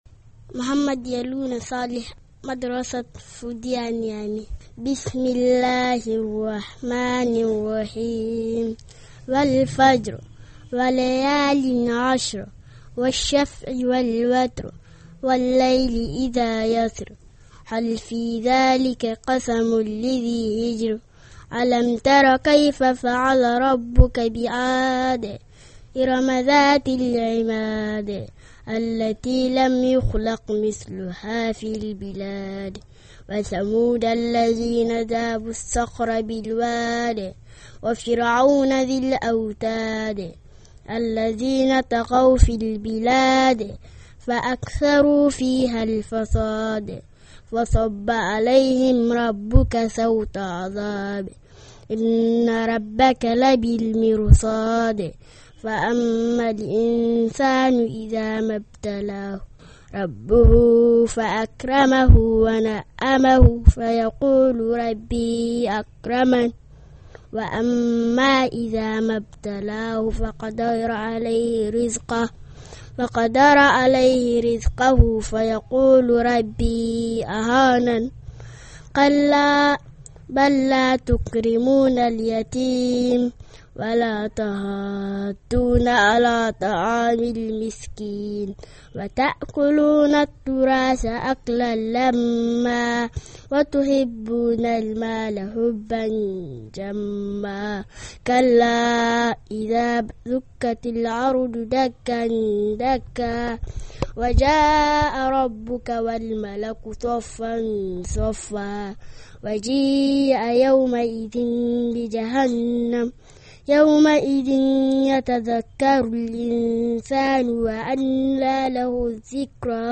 Karatun Kur'ani Mai Tsarki, Nijer